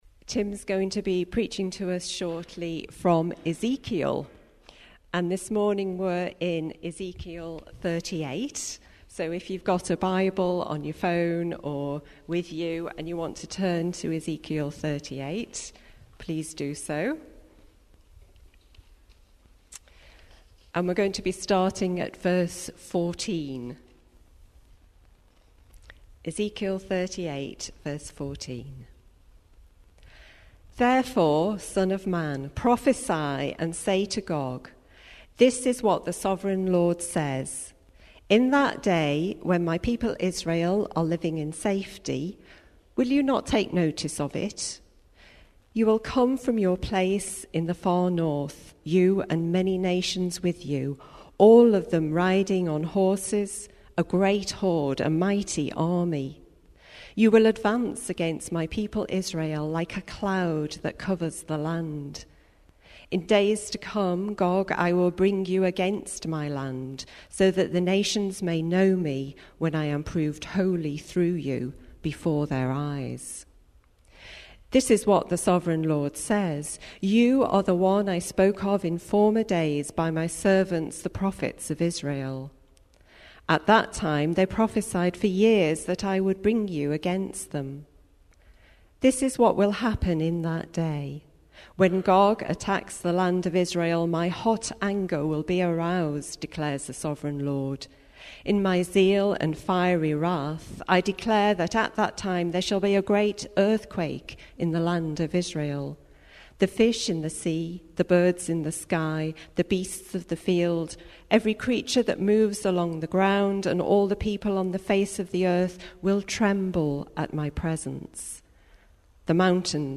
Today's sermon is based on Ezekiel 38 and 39